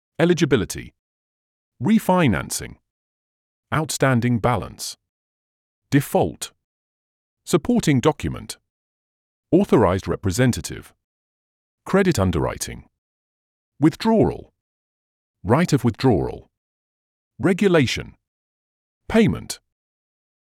Entraînez-vous à prononcer ces mots en anglais. Cliquez sur les icônes fille et garçon pour écouter la prononciation.